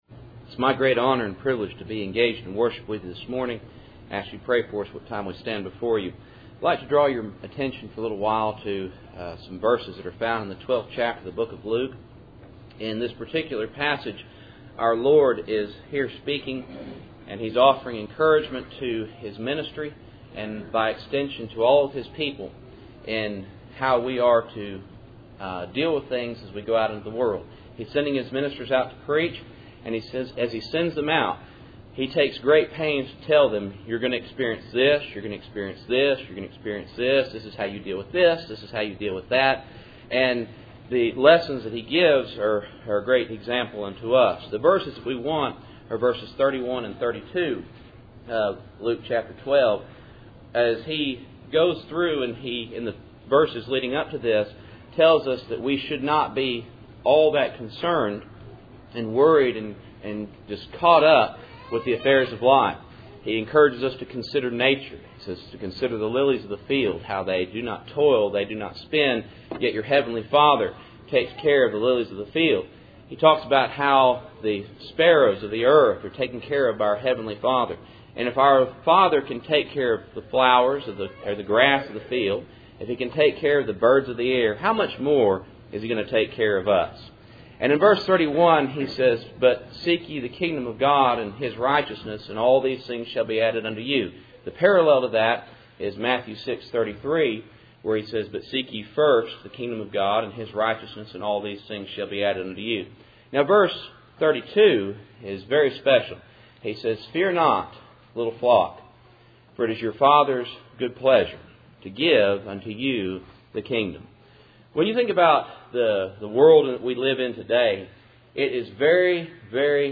Luke 12:31-32 Service Type: Cool Springs PBC Sunday Morning %todo_render% « Alone?